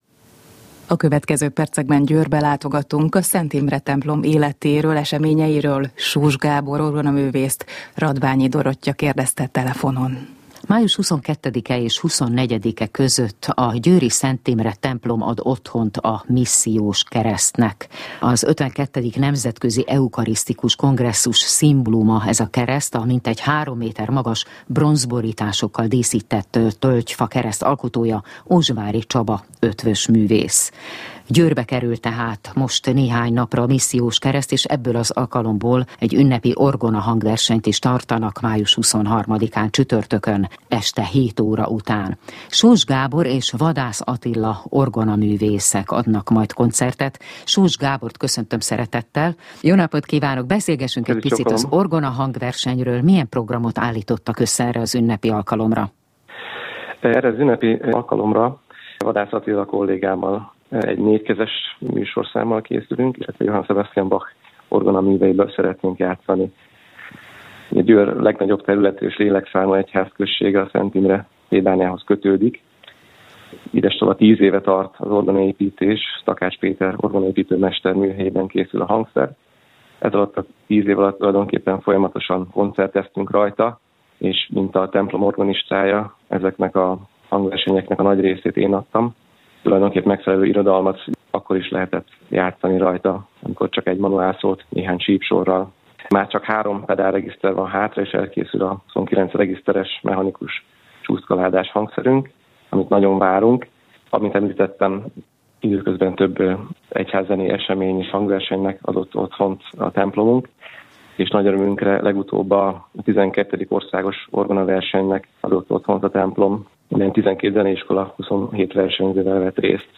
a Bartók Rádió Musica Sacra című műsorának 2019. május 19-i adásában hangzott el: